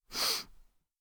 sniff.wav